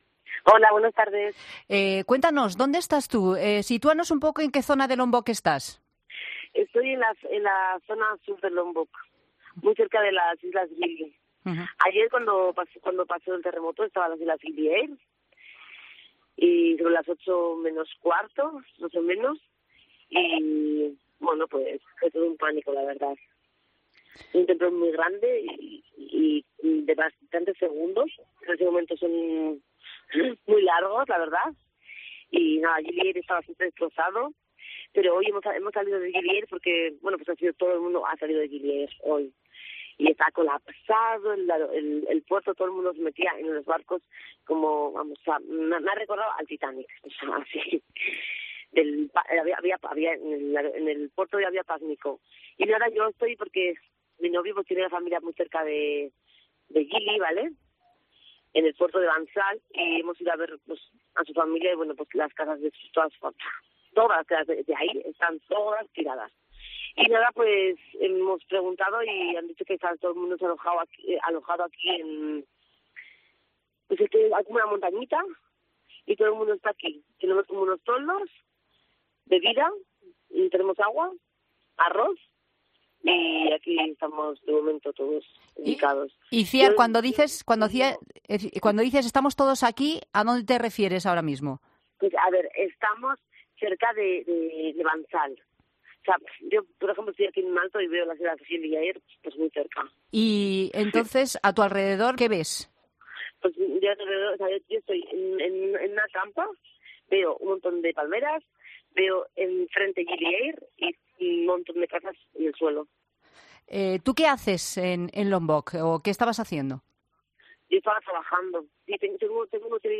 Afectada española por el seísmo de Indonesia: "La gente lo ha perdido todo"